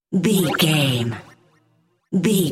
Sound Effects
Atonal
magical
mystical
special sound effects